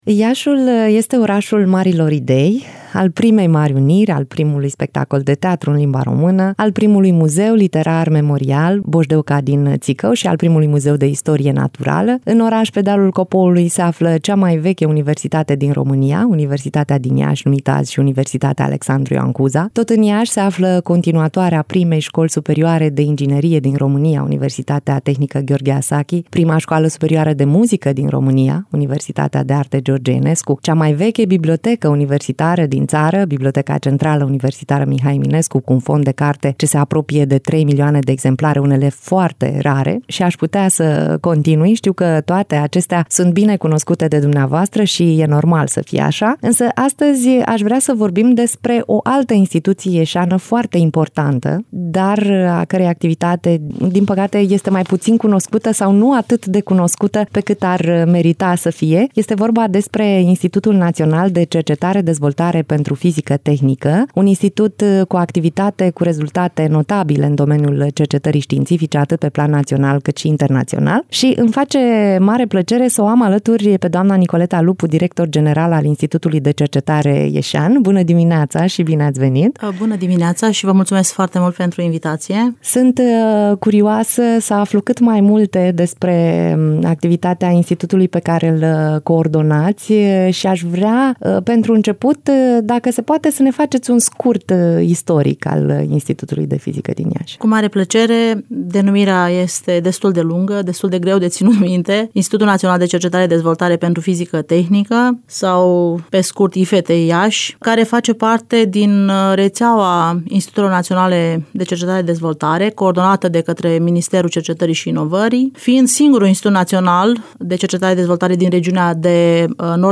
în direct la Radio Iaşi.